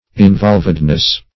Involvedness \In*volv"ed*ness\, n. The state of being involved.